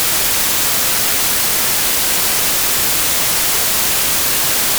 Dither
While today’s noize isn’t very musical, It will hopefully be educational.
Typically it lives far below audible levels.